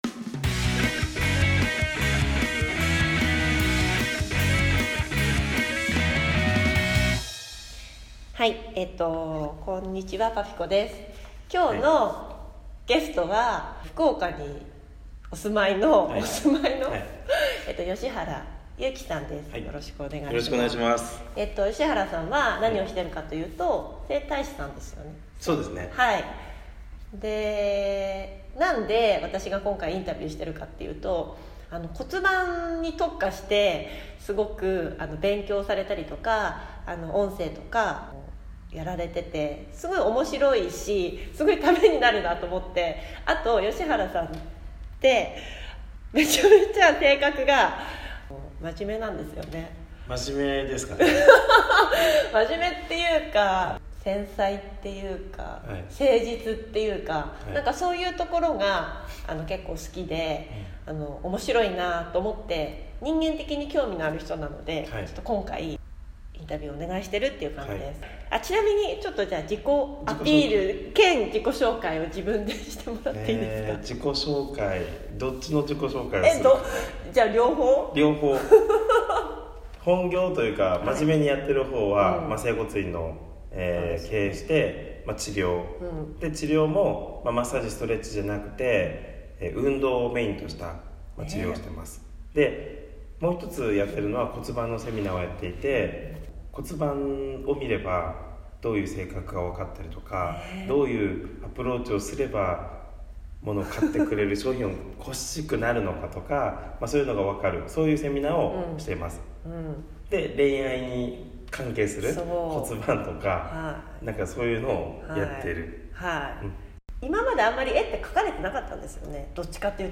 久しぶりの音声配信です。